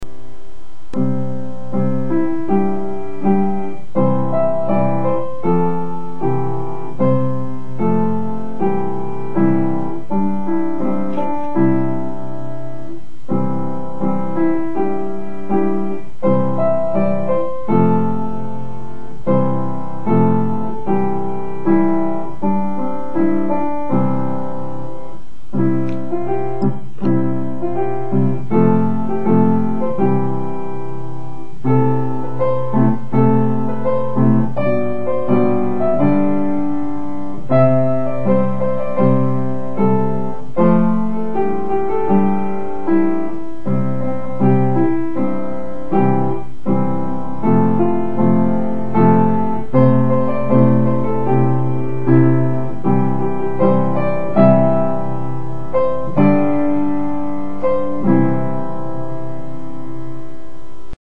校歌
友恭校歌.mp3